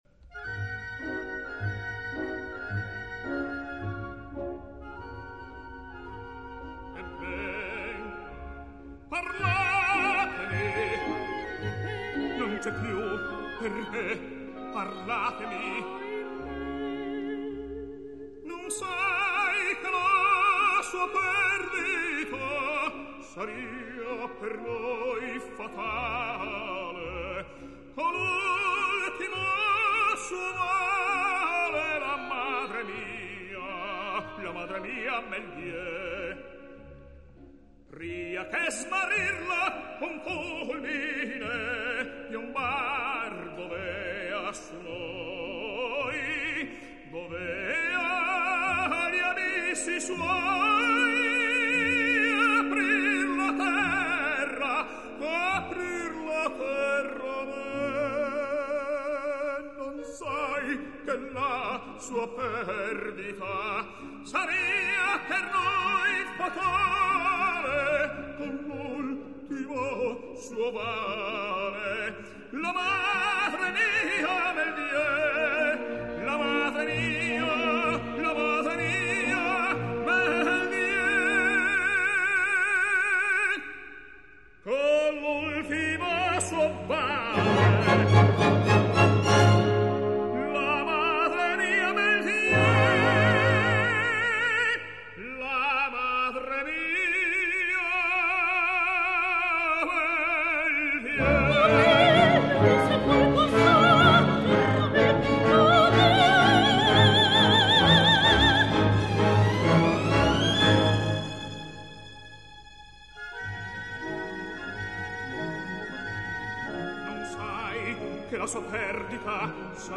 Mina [Sopran]